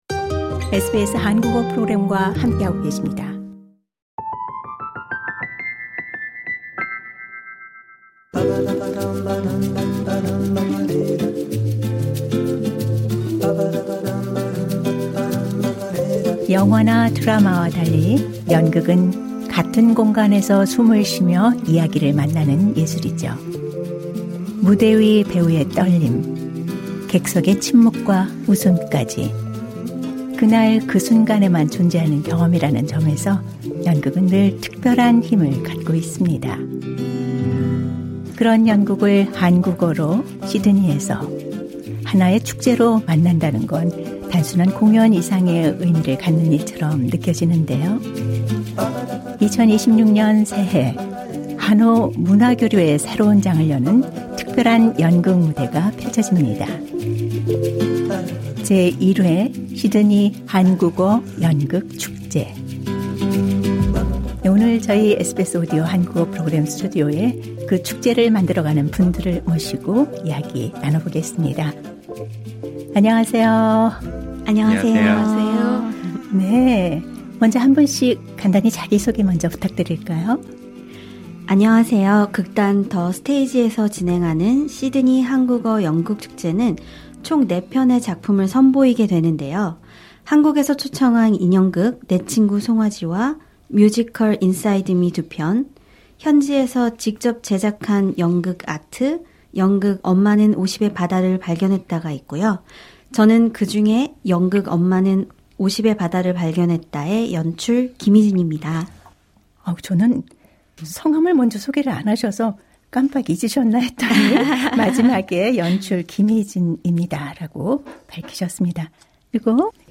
인터뷰: 한국어 연극을 축제로 만나다, '제 1회 시드니 한국어 연극 축제'